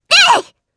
Viska-Vox_Attack2_jp.wav